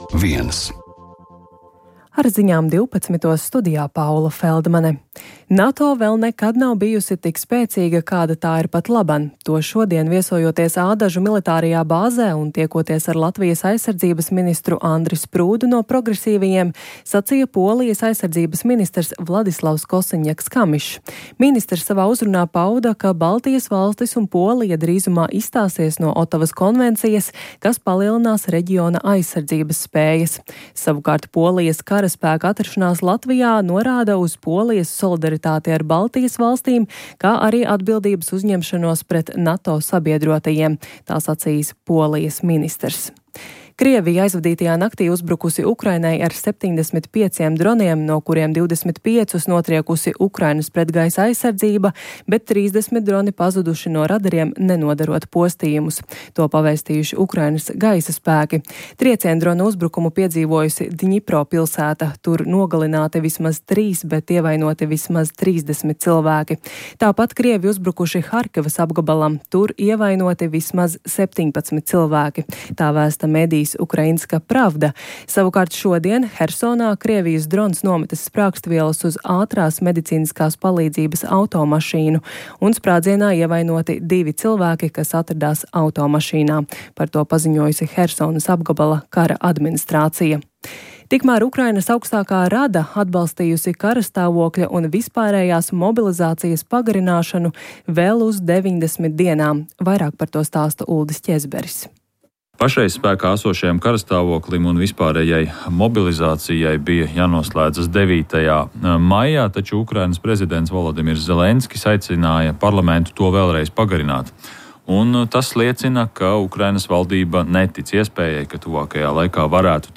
Informatīvi analītiska programma par aktuālo un svarīgo Latvijā un pasaulē. Ziņu dienesta korespondenti ir klāt vietās, kur pieņem lēmumus un risinās notikumi, lai par to visu stāstītu tiešraidēs, reportāžās un izsvērtos komentāros.